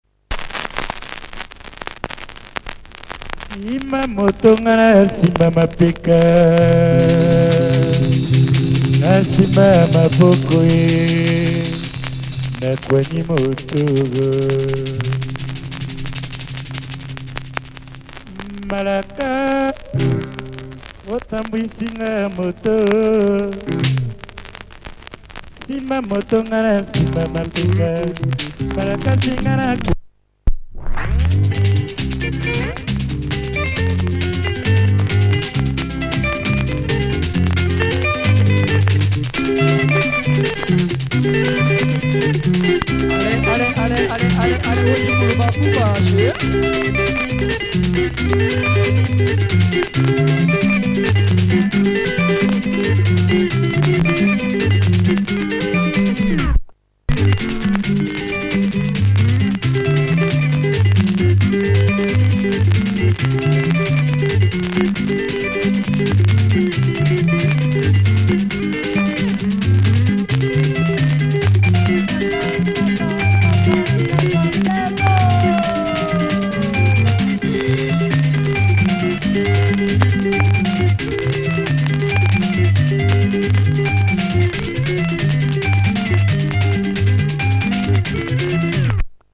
Nice Lingala!